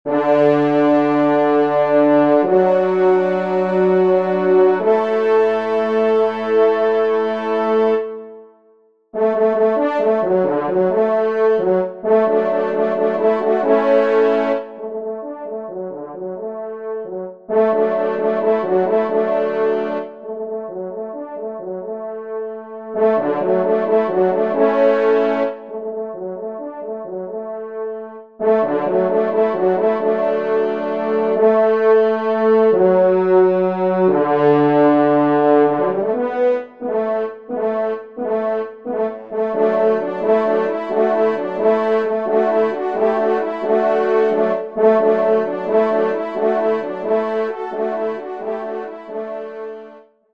Genre : Divertissement pour Trompes ou Cors
Pupitre 3° Cor                   Pupitre 4° Cor